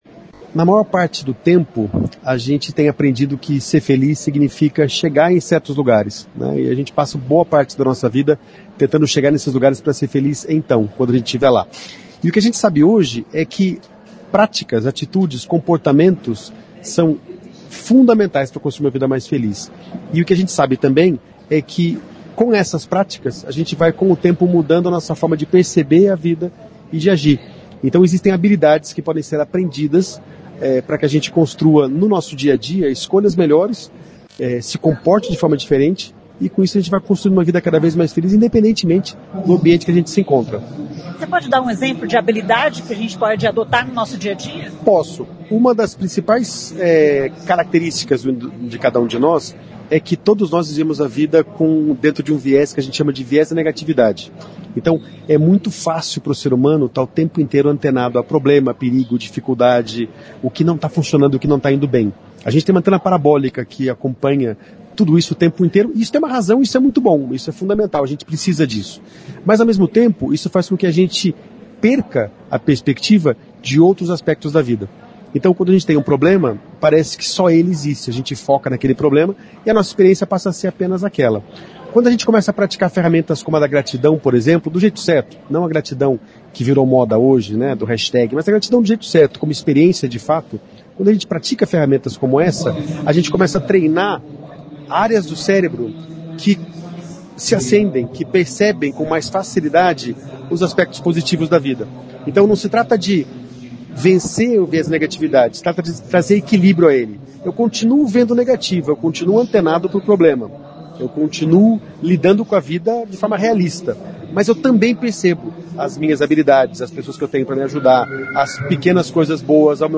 Foi realizada nesta segunda-feira(25), em Maringá, mais uma edição do Estrela ADVB Paraná, evento promovido pela Associação dos Dirigentes de Vendas e Marketing do Brasil, sessão Paraná. O tema foi felicidade.